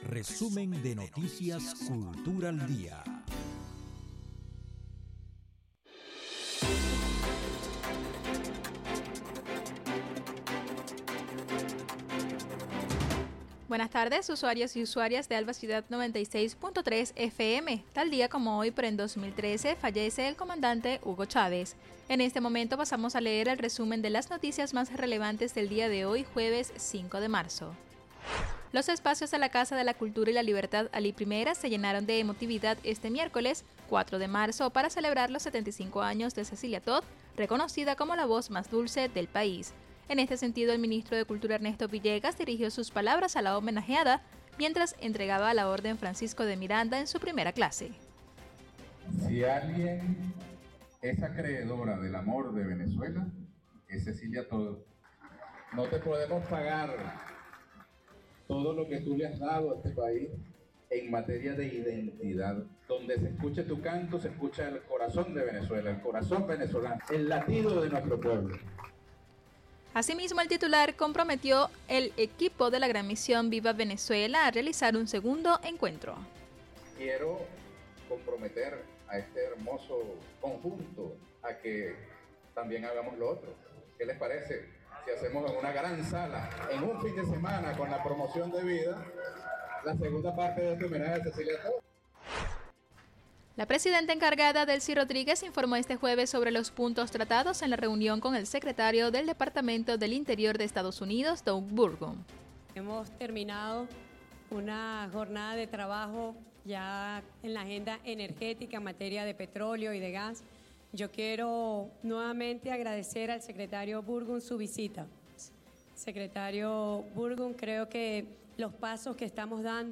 Resumen vespertino de las noticias más importantes del día, elaborado por el Departamento de Prensa.